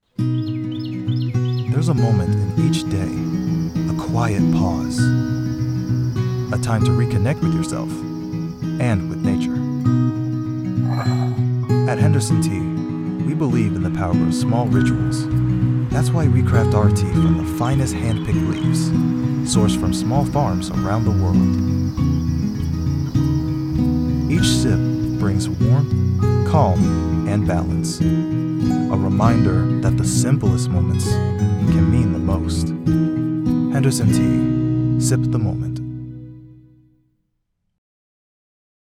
Calm, Warm, and Sincere Commercial